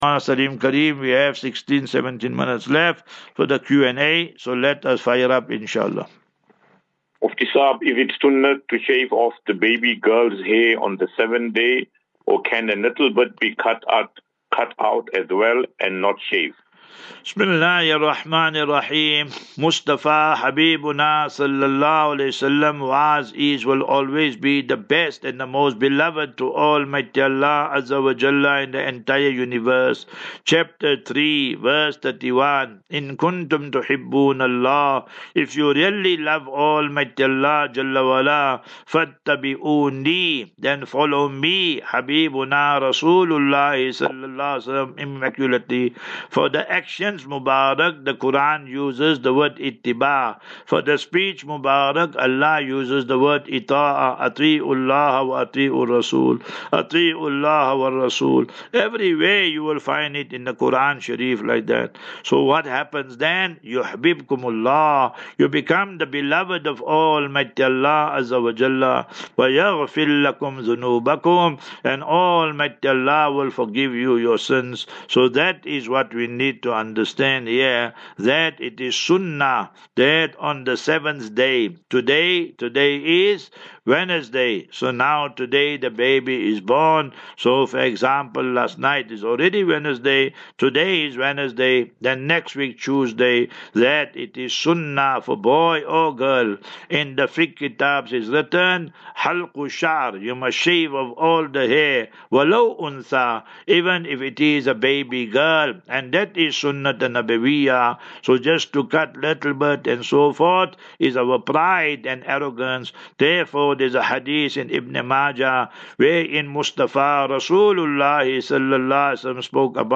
QnA.